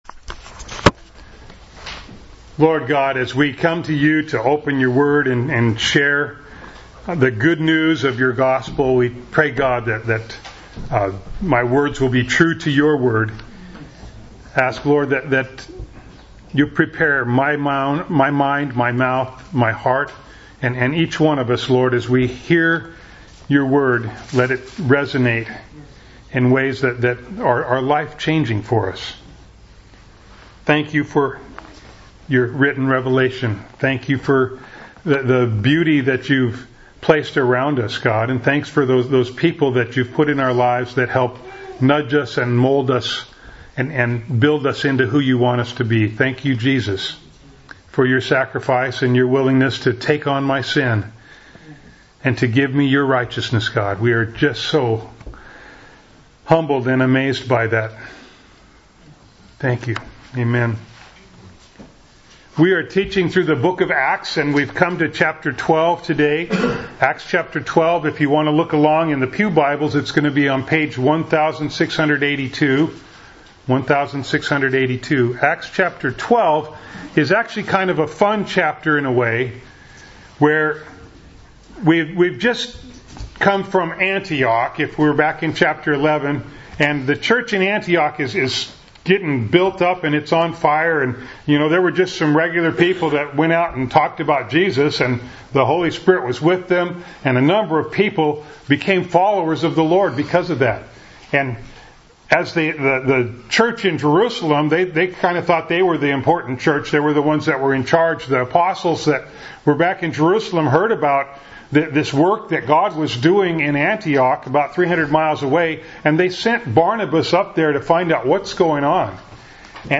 Acts 12:1-25 Service Type: Sunday Morning Bible Text